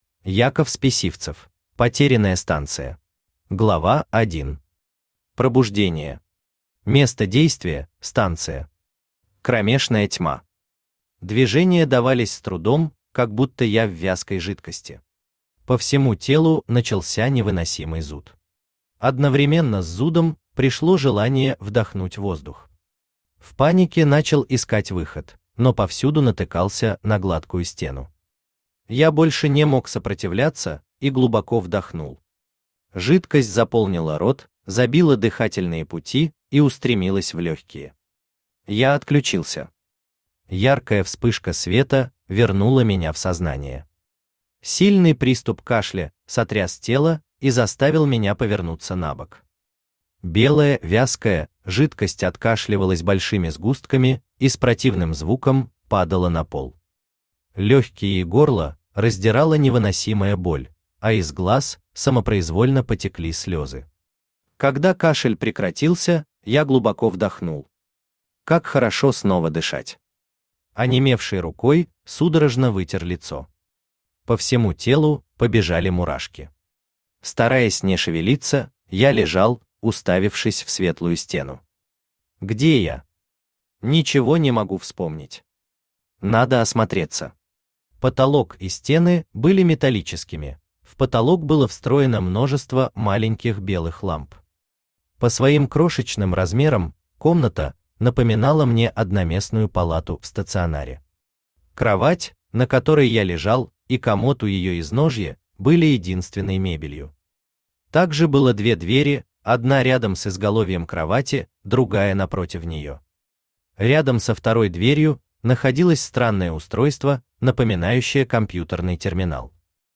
Aудиокнига Потерянная станция Автор Яков Спесивцев Читает аудиокнигу Авточтец ЛитРес.